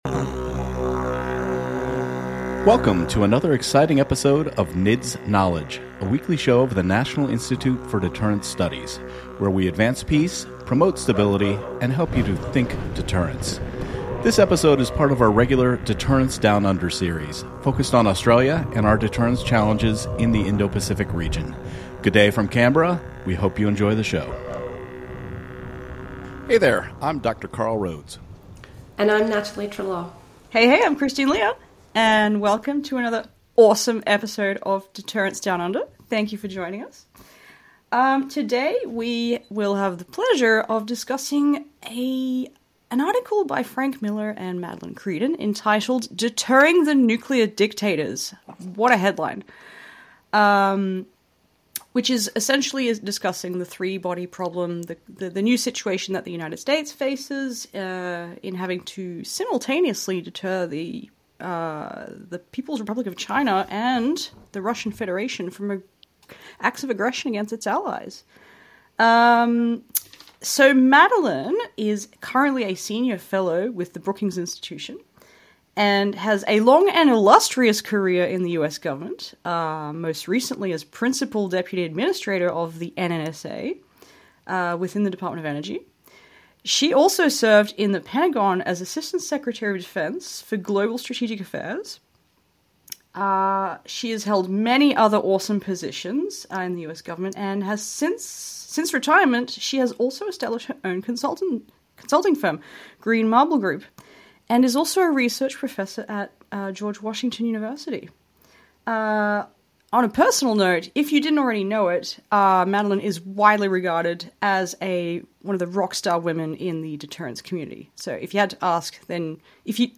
They emphasize the urgency of modernization and the need for a balanced approach between nuclear and conventional capabilities. The conversation also explores the role of allies in the Asia-Pacific region, the potential for an Asian NATO, and the implications of nuclear proliferation among friendly nations.